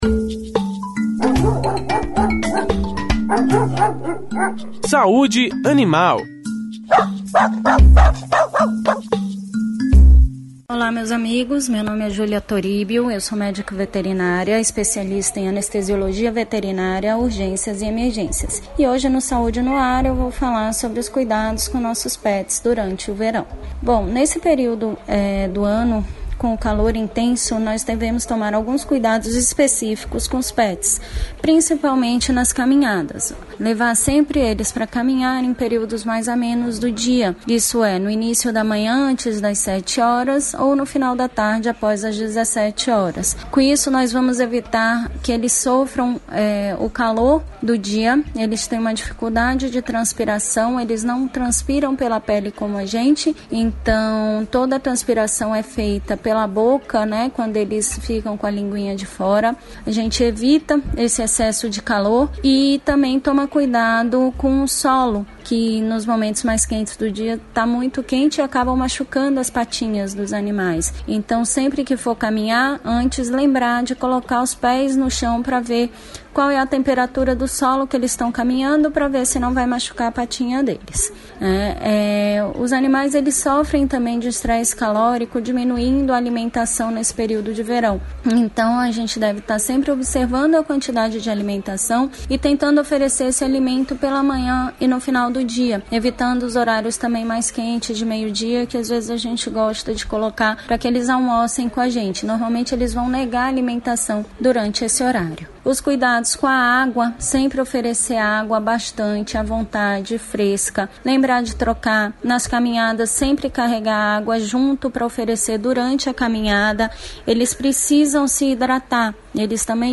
O quadro Saúde animal que vai ao ar no Programa Saúde No Ar é exibido pela Rede Excelsior de Comunicação: Rádio Excelsior AM 840 e  web Saúde No Ar